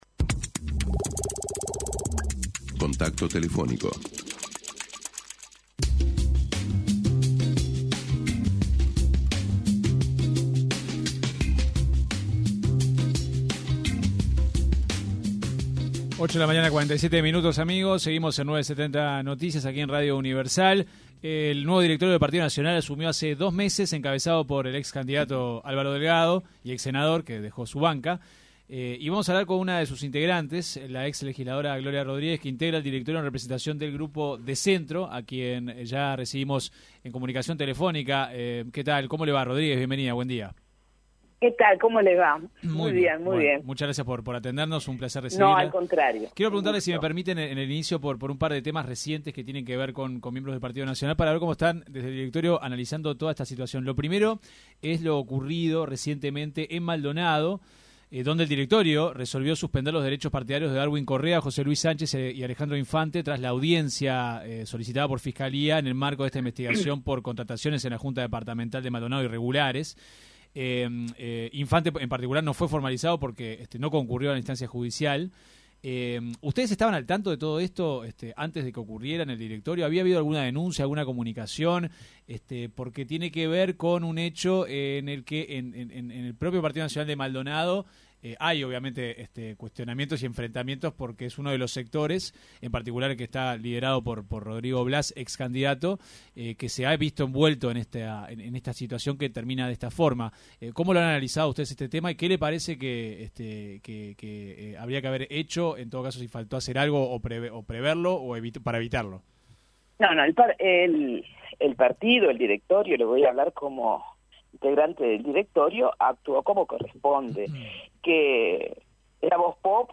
La integrante del directorio del Partido Nacional, Gloria Rodríguez en entrevista con 970 Noticias dio su visión sobre el pedido de la ex diputada Valentina Dos Santos quien pidió la reafiliación a la agrupación política.